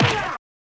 Roland.Juno.D _ Limited Edition _ GM2 SFX Kit _ 05.wav